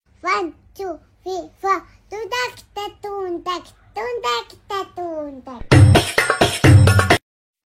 با صدای بچه